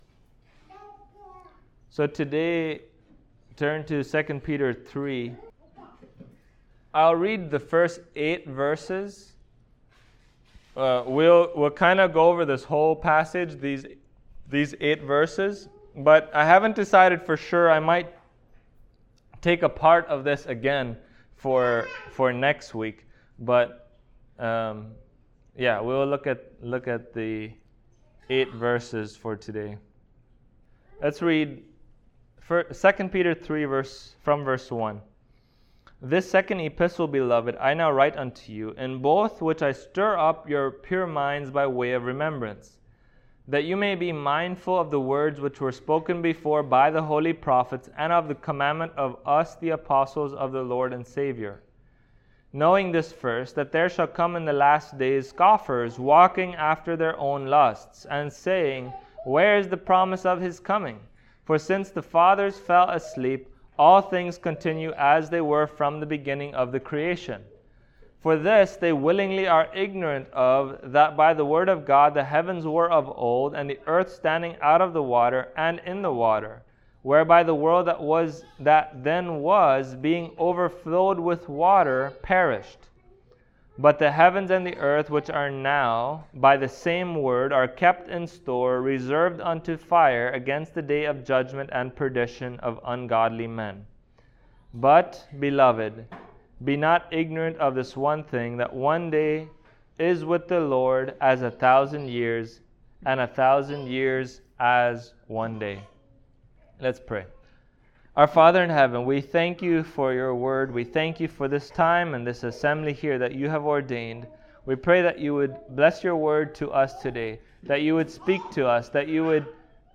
2 Peter 3:1-8 Service Type: Sunday Morning Topics: God's Judgment « The Tragedy of Almost Saved God’s Patience and the Certainty of Judgment.